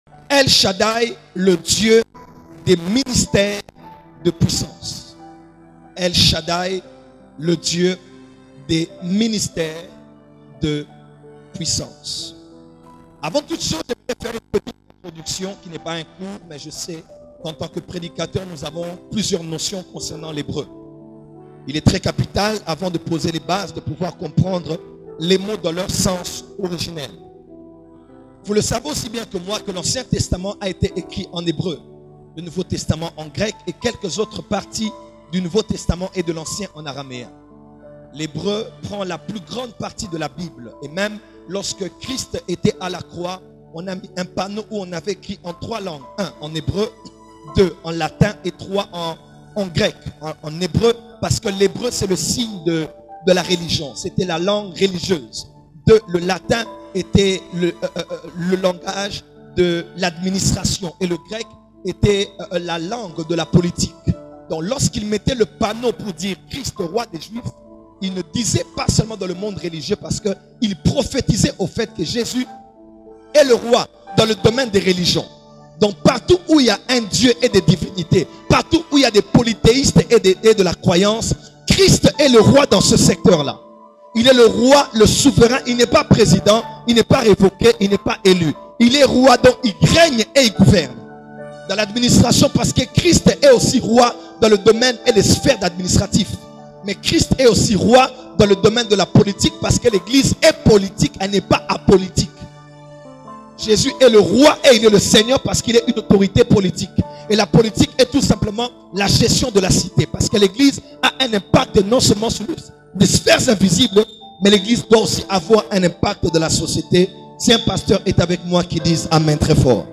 PRÉDICATIONS AUDIO | SCHOOL FOR CHRIST FONDATION